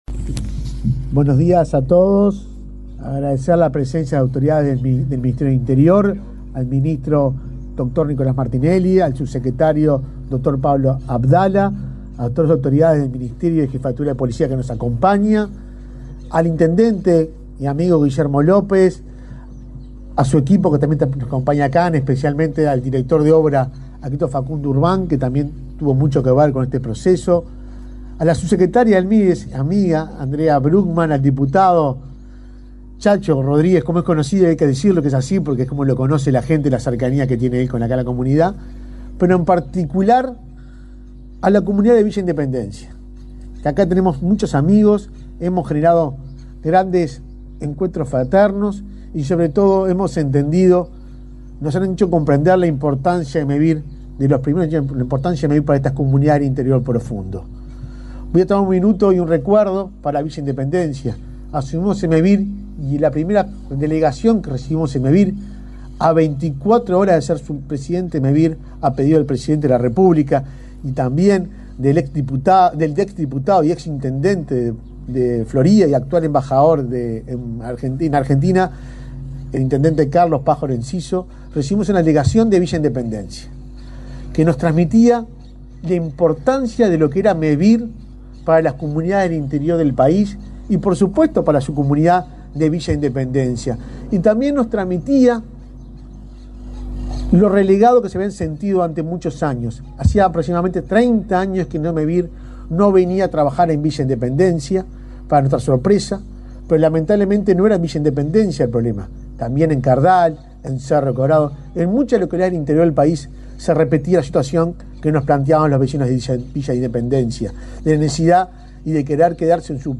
Palabras de autoridades en acto en Florida
Palabras de autoridades en acto en Florida 29/08/2024 Compartir Facebook X Copiar enlace WhatsApp LinkedIn El presidente de Mevir, Juan Pablo Delgado, y el director de la Policía Nacional, José Azambuya, participaron en el acto de inauguración de una subcomisaría en Villa Independencia, en el departamento de Florida.